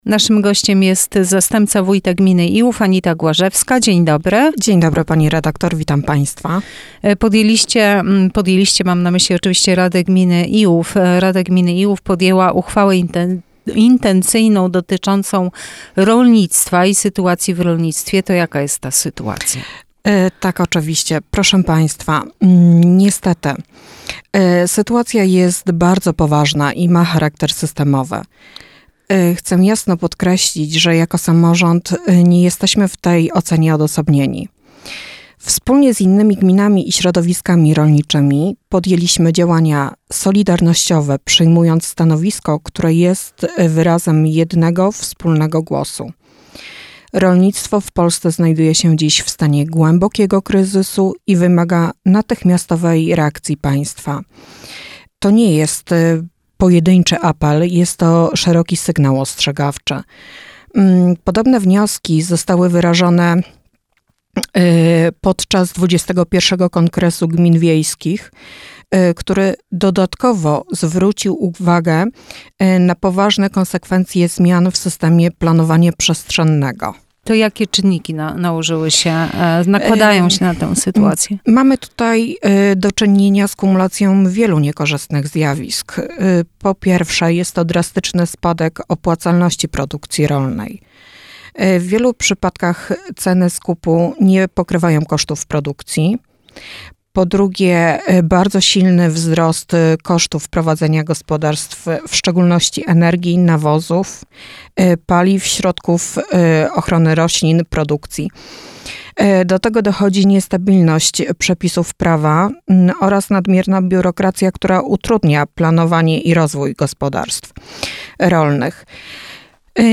Wywiad z Anitą Głażewską, Zastępcą Wójta Gminy Iłów w Radio Sochaczew - Najnowsze - Gmina Iłów